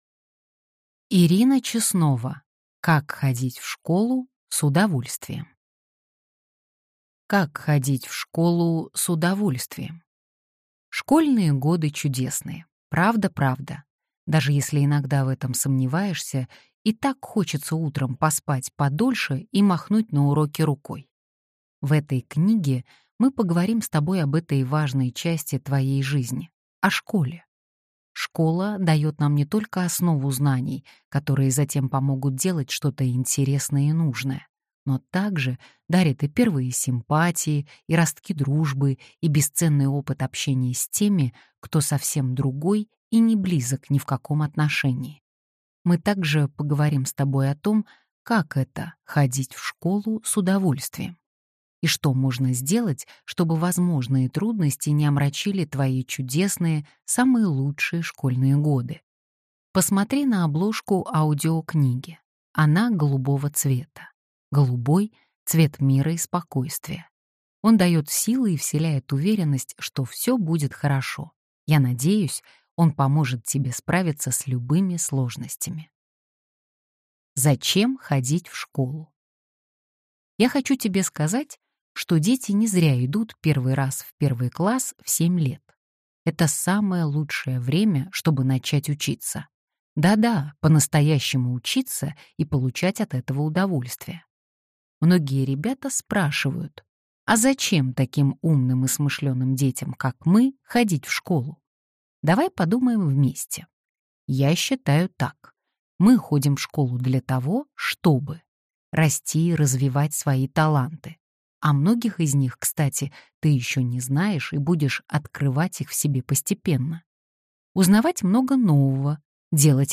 Аудиокнига Как ходить в школу с удовольствием | Библиотека аудиокниг